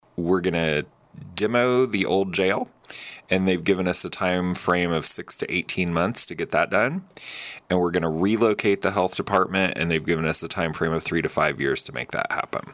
Saline County Administrator Phillip Smith-Hanes joined in on the KSAL Morning News Extra with a look at how the results of the study will become a reality in the coming months.